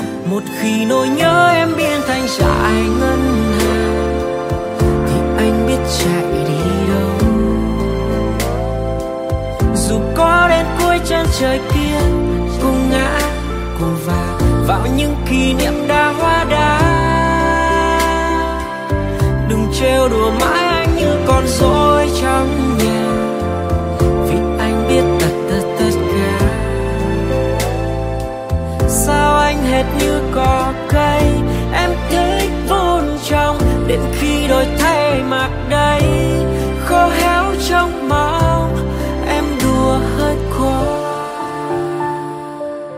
Ballad Version